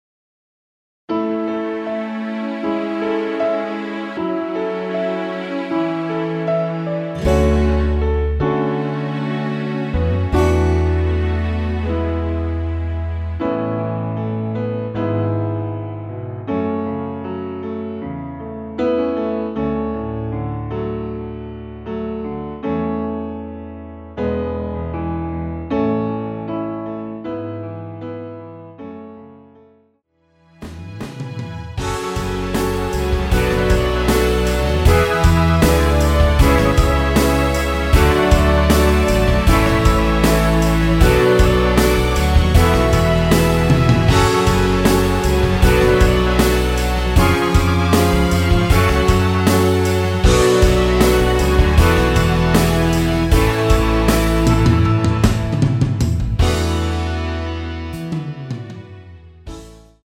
MR 입니다.
앞부분30초, 뒷부분30초씩 편집해서 올려 드리고 있습니다.
중간에 음이 끈어지고 다시 나오는 이유는